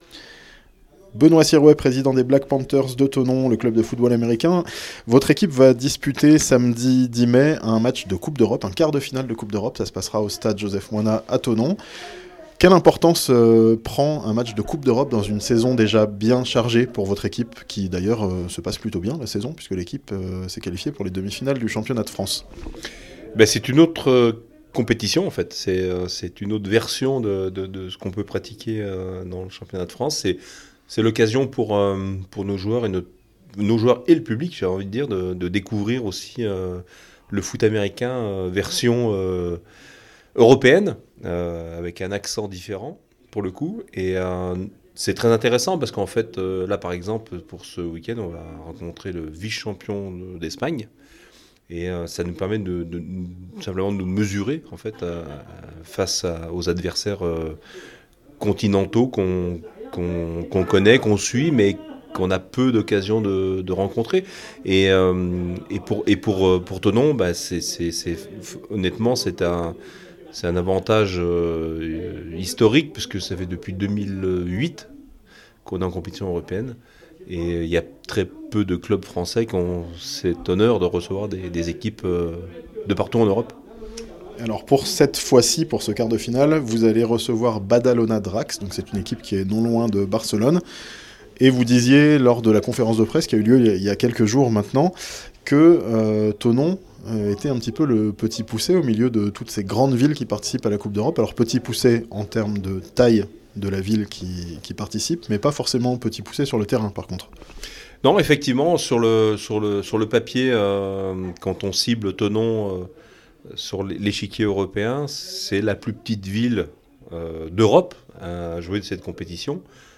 Les Black Panthers à la conquête de l'Europe, ce samedi à Thonon (interview)